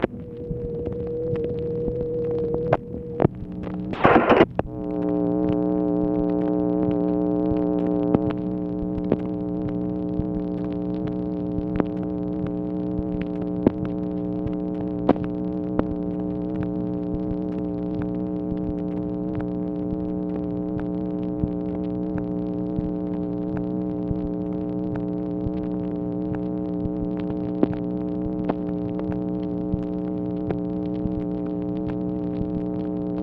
MACHINE NOISE, September 4, 1964
Secret White House Tapes